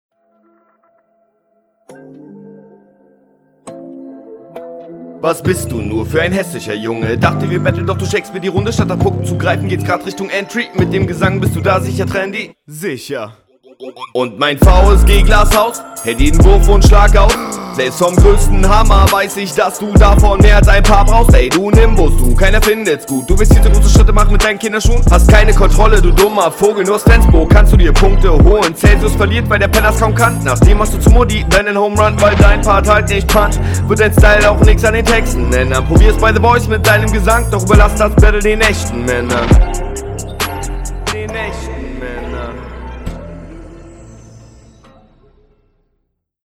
Weniger Flow, mehr Punchlines.